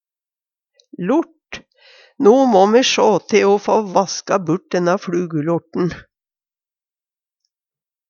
lort - Numedalsmål (en-US)